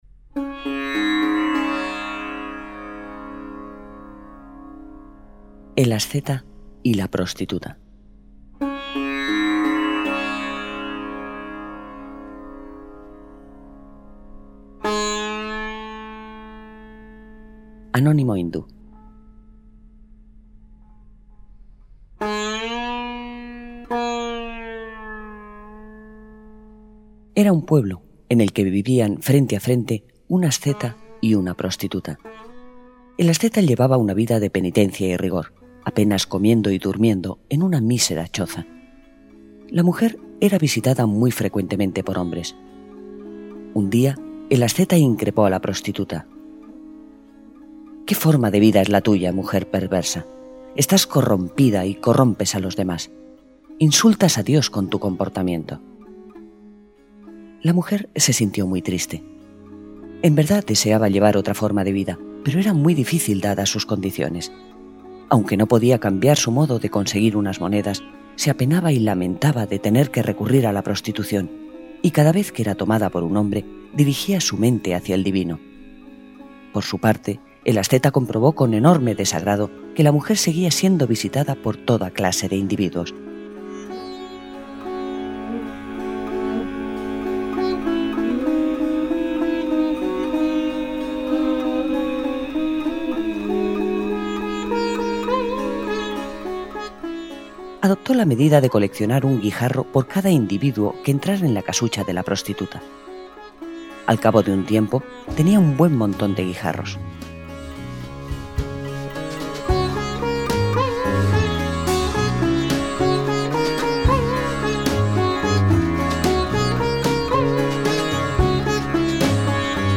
Audiolibro: El asceta y la prostituta
Música: Blancheneige Bazaar Orchestra (cc:by-sa)